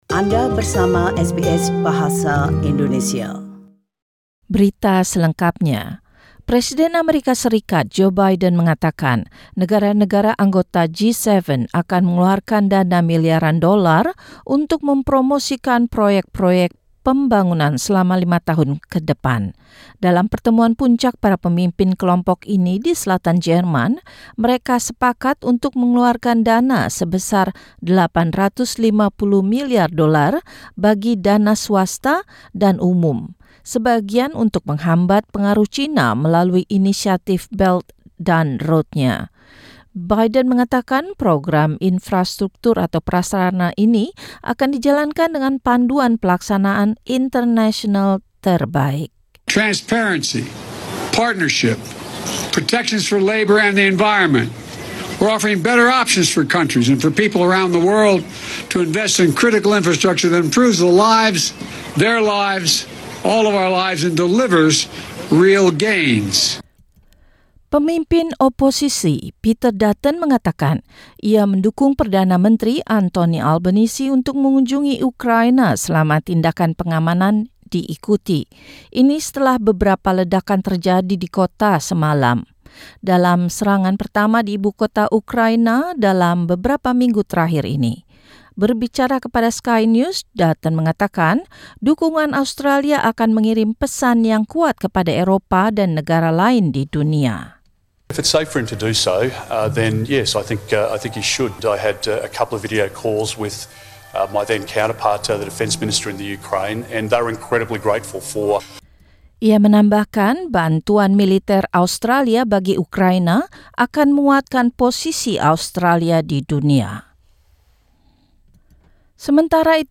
SBS Radio News in Indonesian - Wednesday, 29 June 2022